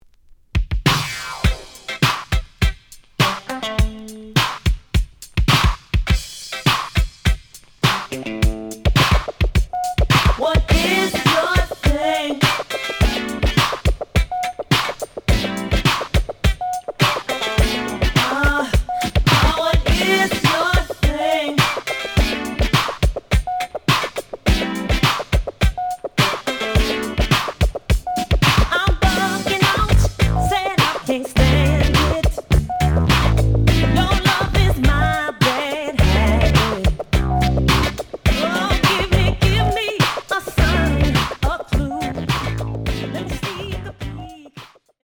The audio sample is recorded from the actual item.
●Genre: Funk, 80's / 90's Funk
Slight edge warp.